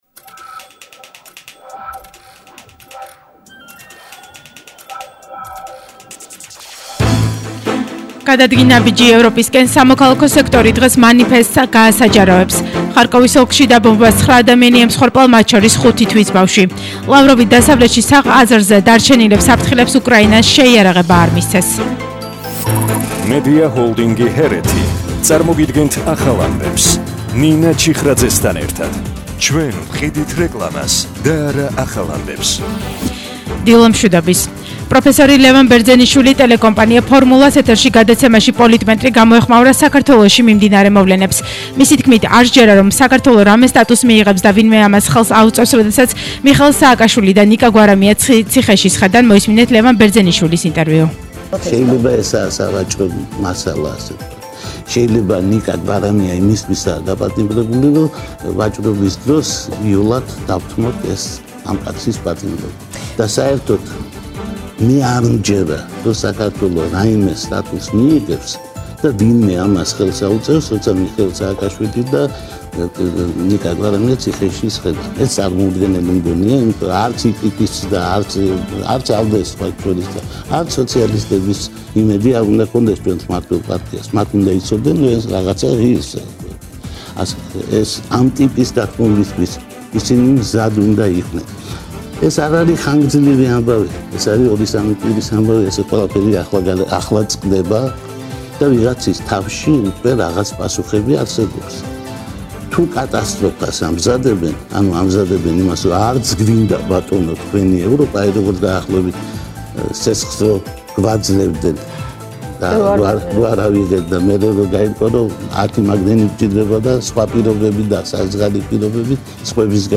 ახალი ამბები 10:00 საათზე – 27/05/22